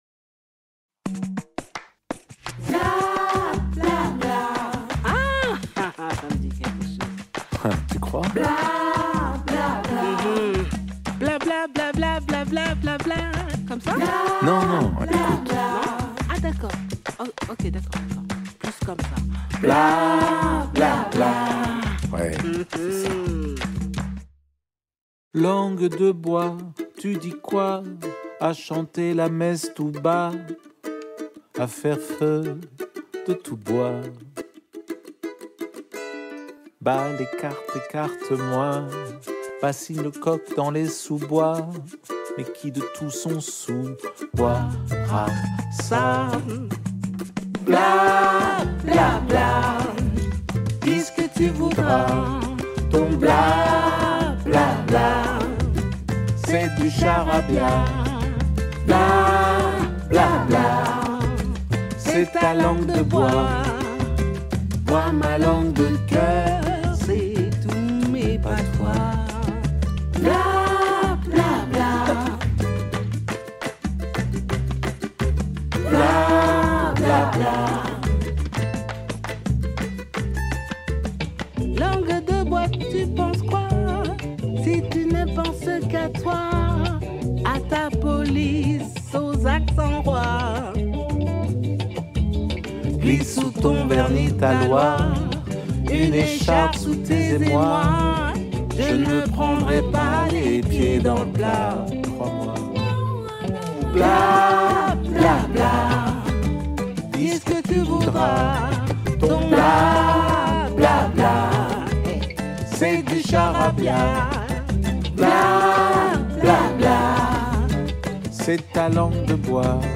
duo solaire